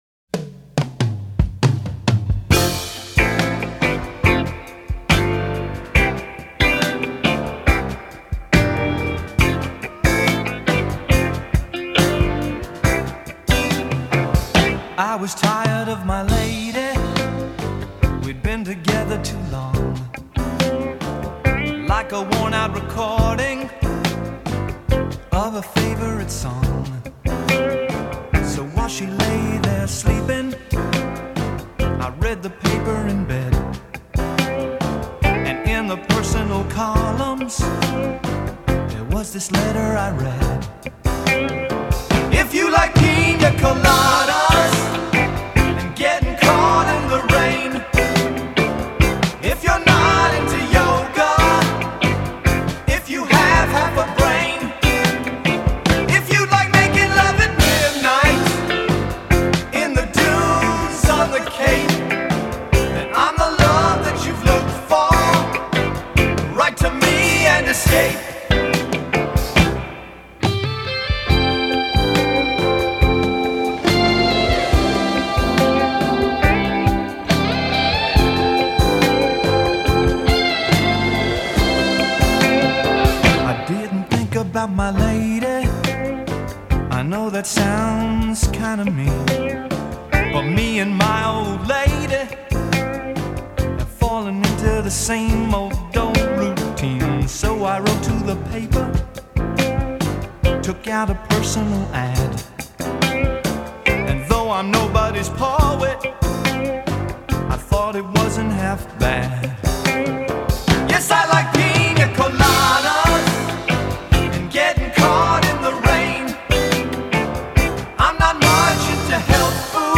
full of lesser-known ’70s pop fodder.